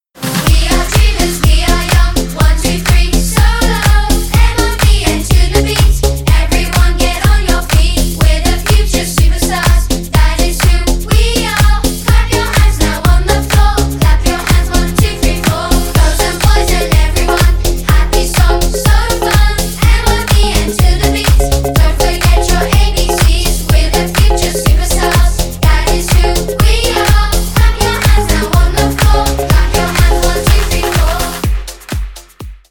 Categoria Elettronica